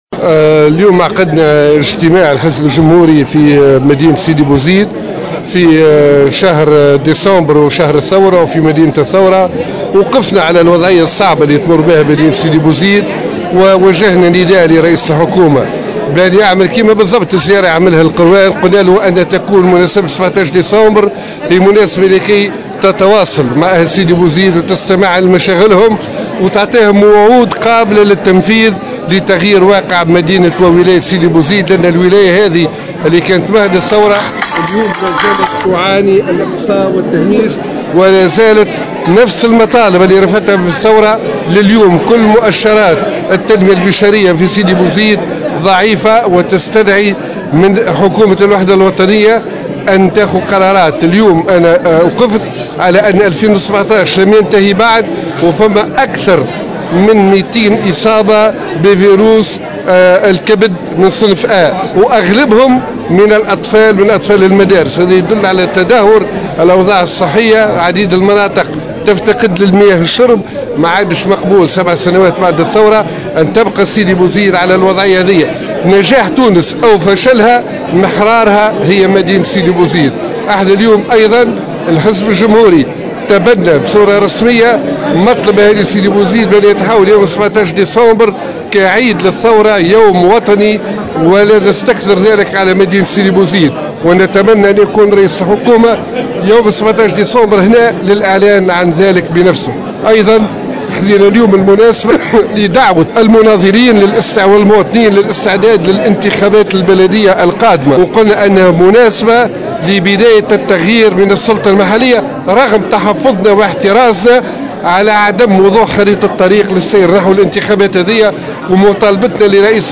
شدد الامين العام للحزب الجمهوري عصام الشابي في تصريح لمراسل الجوهرة "اف ام" خلال اشرافه على إجتماع لحزبه اليوم الأحد بسيدي بوزيد بمناسبة احياء الذكرى السابعة لثورة 17 ديسمبر على ضرورة اعتماد يوم 17 ديسمبر من كل سنة كعيد وطني.